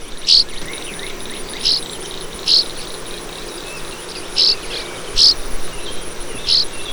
Northern Rough-winged Swallow
Stelgidopteryx serripennis